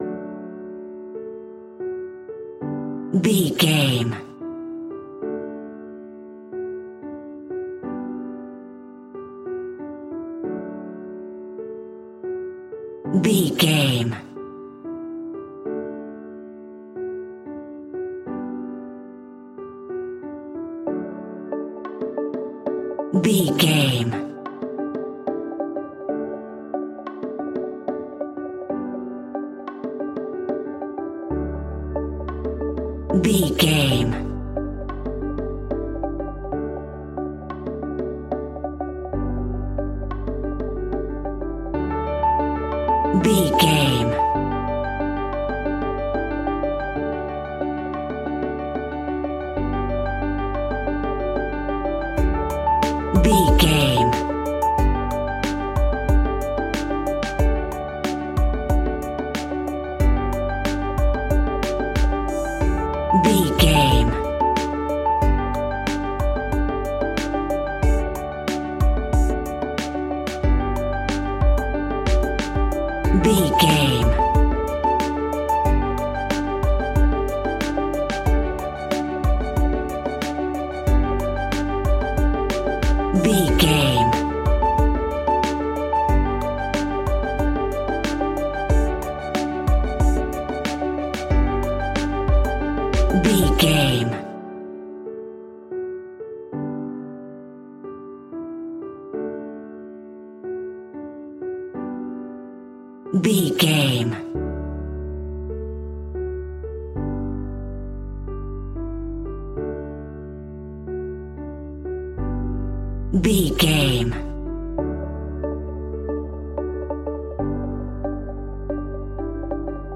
Ionian/Major
D
pop rock
indie pop
energetic
motivational
upbeat
groovy
guitars
bass
drums
piano
organ